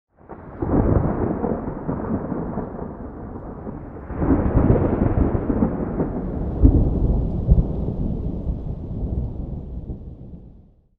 Divergent/storm_4.ogg at main
storm_4.ogg